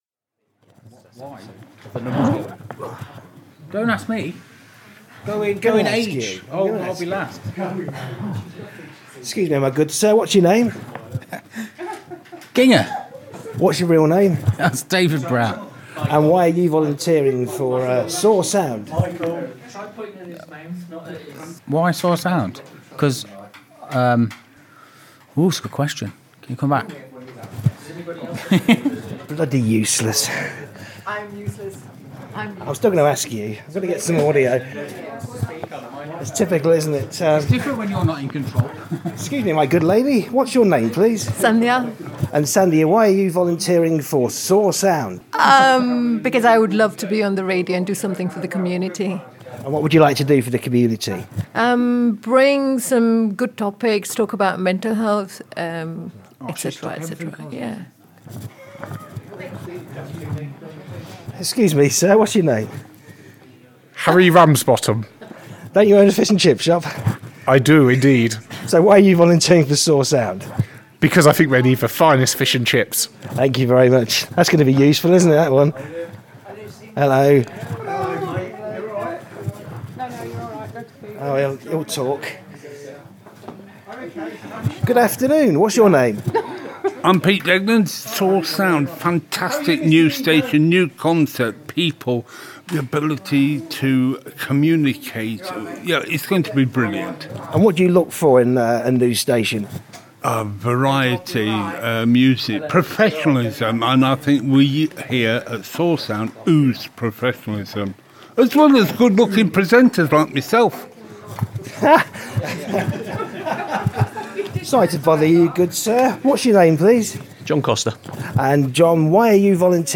On 10th March 2024, volunteers for Soar Sound, a new community radio station for Leicester and Leicestershire, convened to share their motivations for joining the initiative.